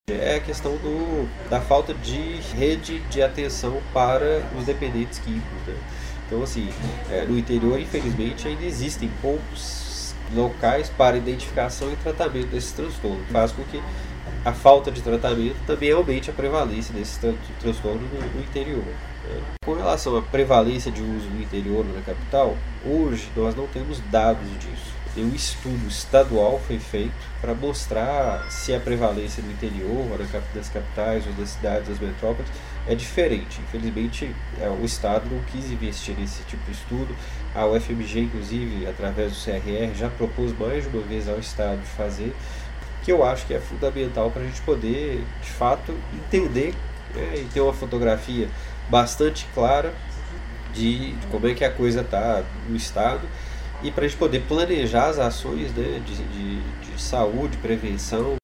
Especialista fala sobre a realidade atual do consumo de drogas lícitas e ilícitas no interior.